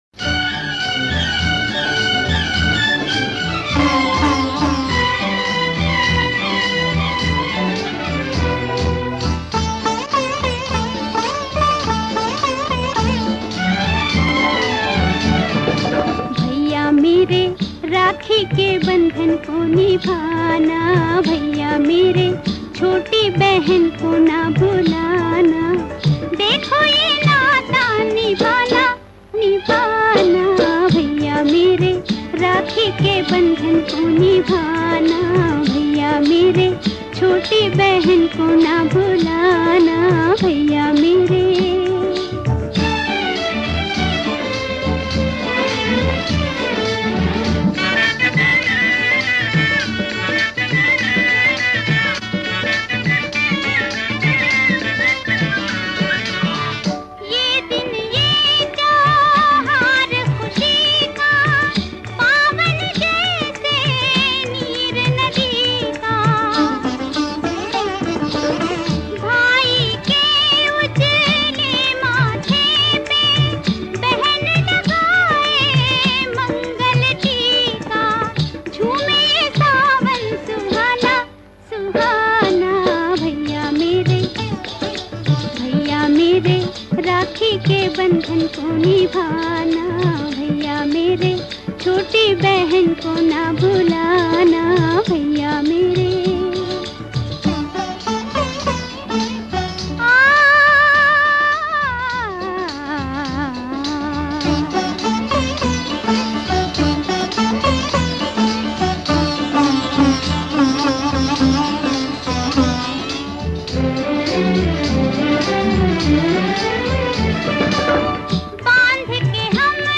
ethereal voice
evergreen melody